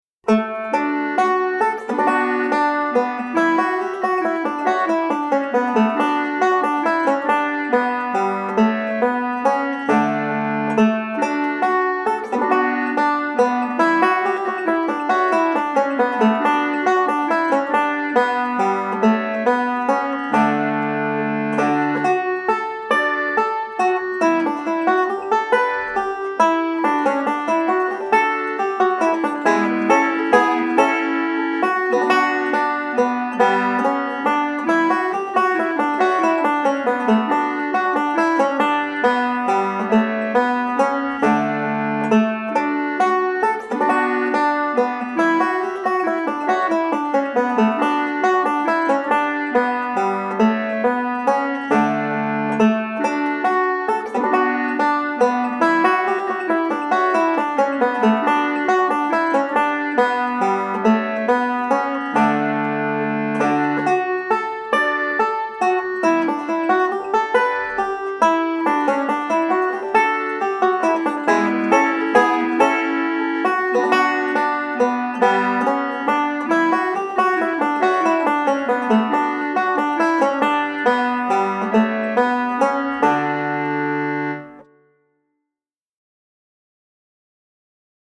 5-STRING BANJO
SOLO
Welch and Irish